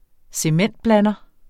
Udtale [ -ˌblanʌ ]